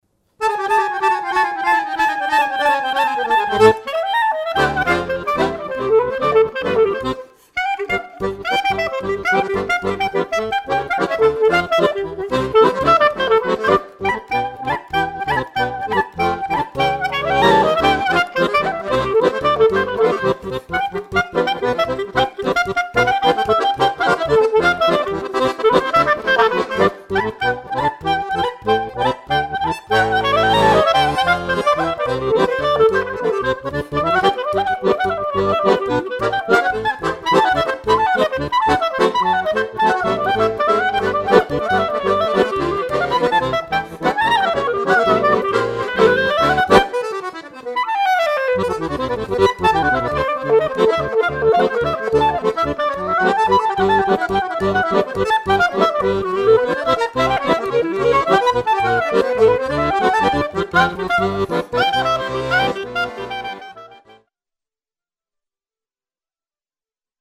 accordéon, guitare
clarinette